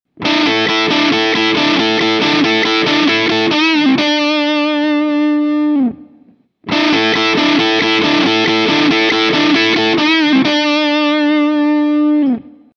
In each file I recorded the same phrase with a looper: fiirst with the Original Big Muff inserted in a loop just before the Stack and after with the Kemper Stomps. I used HiWatt profiles here, but same results I obtained with Marshall and Fender Amps…
Here some Audio Tests (in each phrase is played first the Original Muff and then the Kemper Emulation):
TEST-BigMuff-Ram-03.mp3